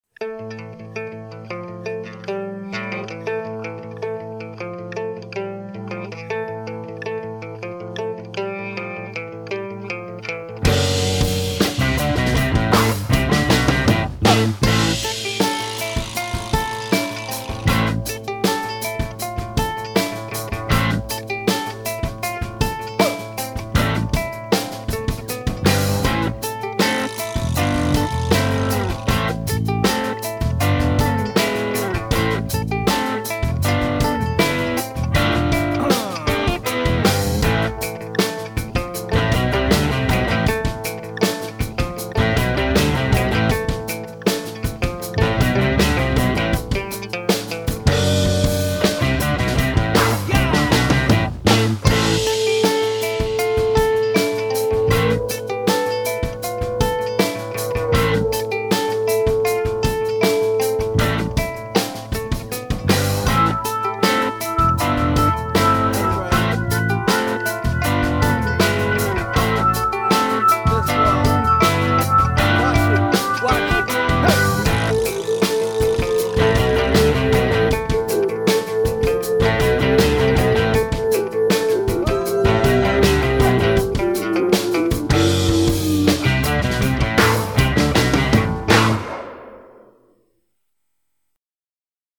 Love this instrumental track, a quick hitter too!